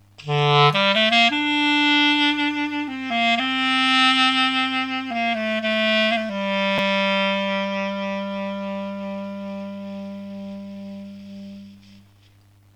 habe gerade kein Sax zur Hand, aber die alte Klarinette tut es auch:
Ich habe die oberen Töne der Melodie mal analysiert, sie schwanken mit ca. 2 Hertz über bzw. unter D4 und C4.
Die Durchschnittsstonhöhe bleibt beim Vibrato exakt dieselbe wie bei dem gerade geblasenen Ton vom Anfang.
Ich habe dabei gemerkt, dass ich das Vibrato immer mit steigendem Druck beginne, die Tonhöhe also erst ansteigt und dann abfällt, etwa so: "aaaaaaaaioioioi".
vibrato.wav